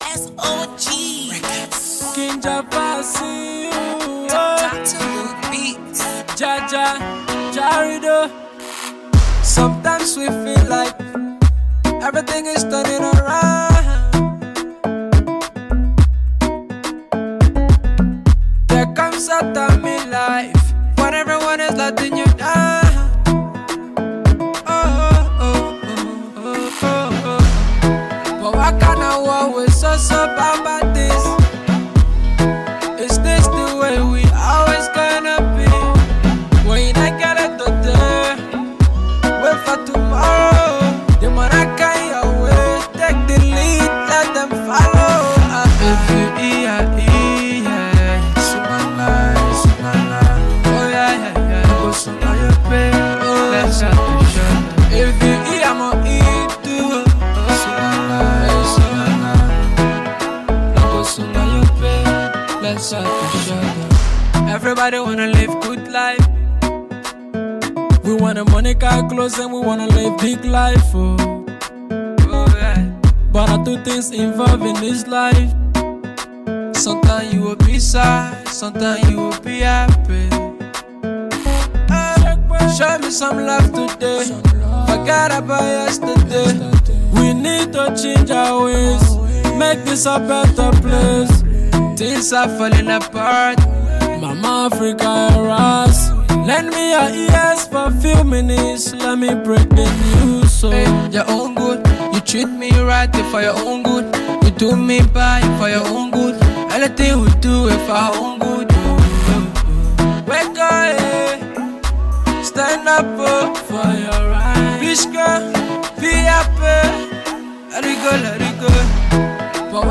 / Afrobeats/Afro-Pop, Colloquial / By